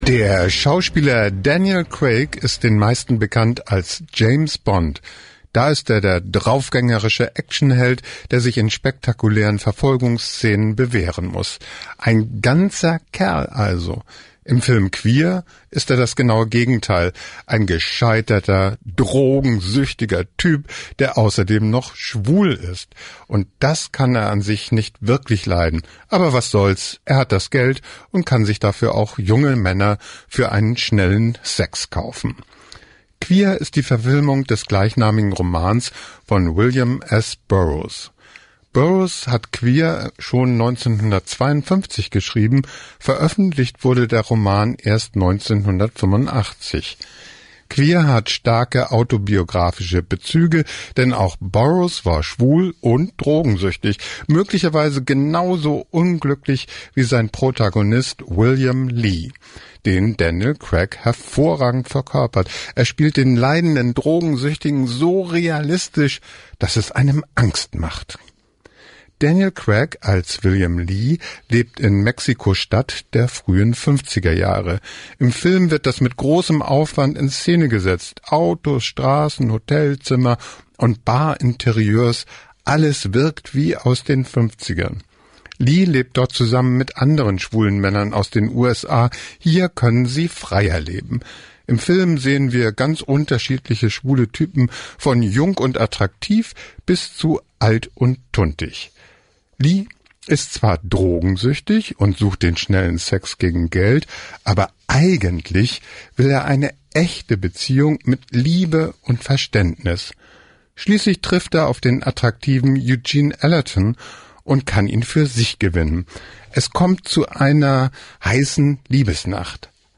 Eine Filmkritik